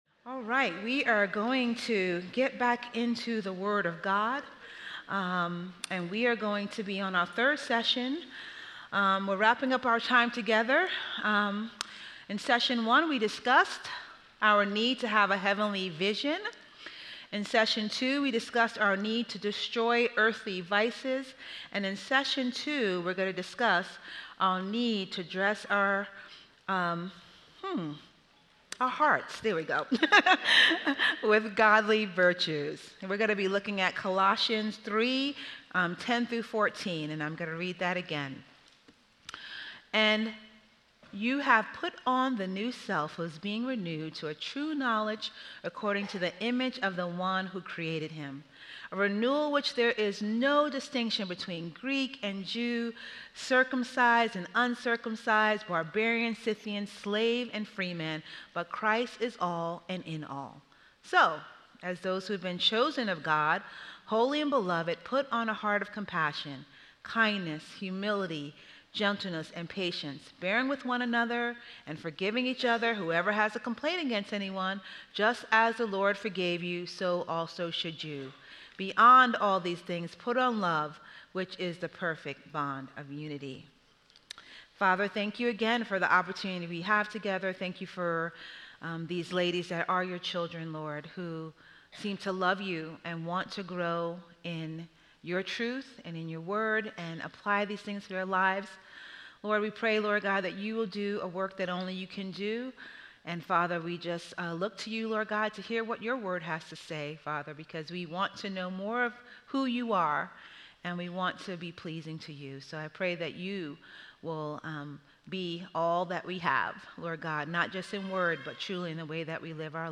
Women Women's Fall Conference - 2025 Audio Video ◀ Prev Series List Next ▶ Previous 2.